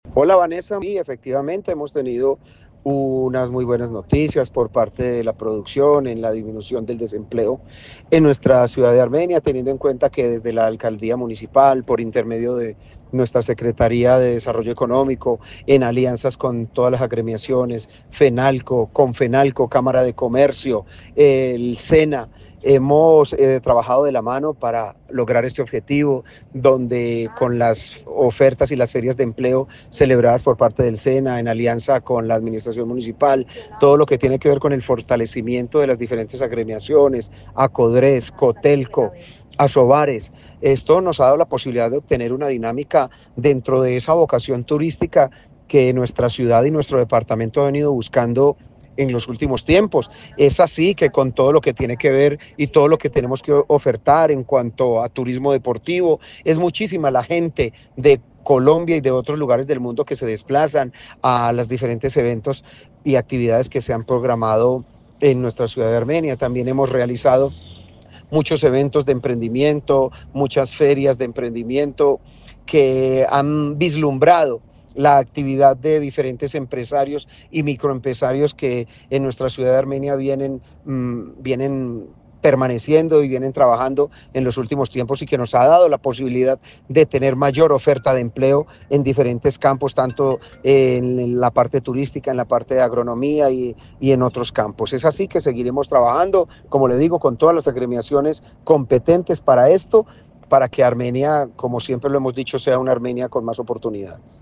Alcalde de Armenia, James Padilla